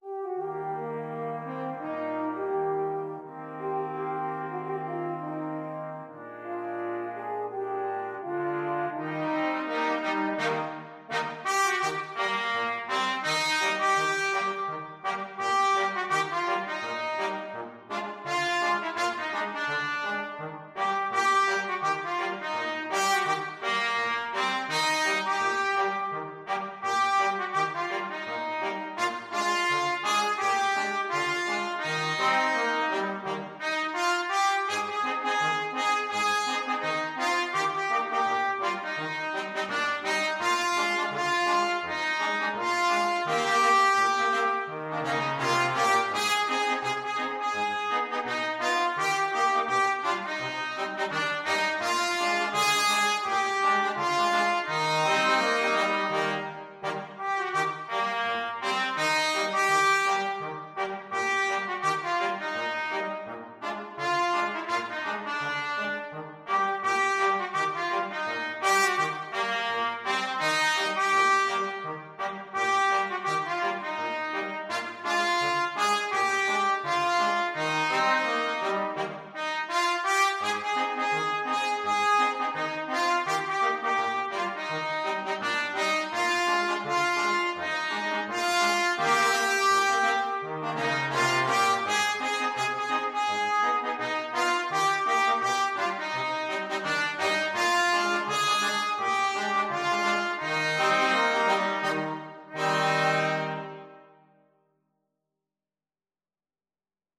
Trumpet 1Trumpet 2French HornTrombone
Calmly =c.84
2/2 (View more 2/2 Music)